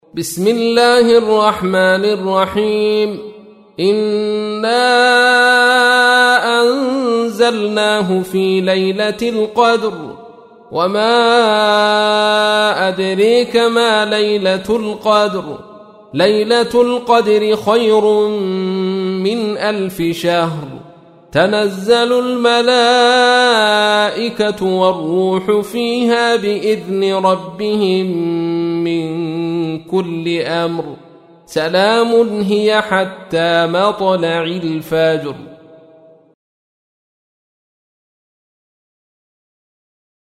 تحميل : 97. سورة القدر / القارئ عبد الرشيد صوفي / القرآن الكريم / موقع يا حسين